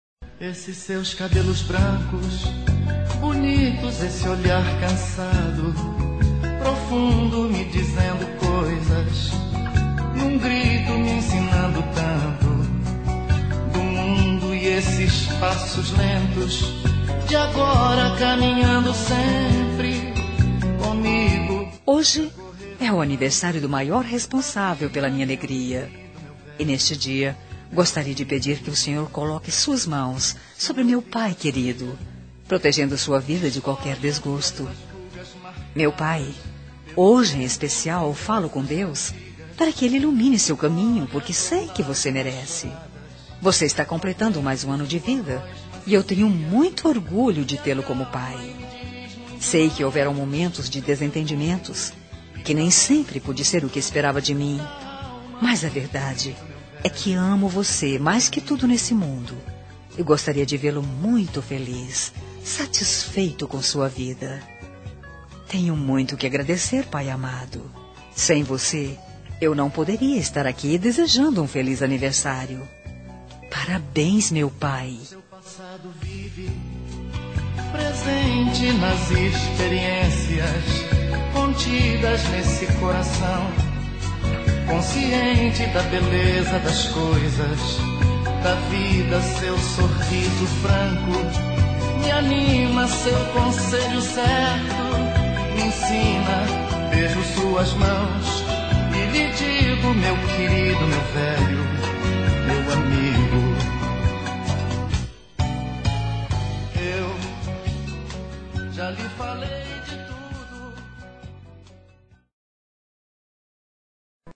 Telemensagem de Aniversário de Pai – Voz Feminina – Cód: 1471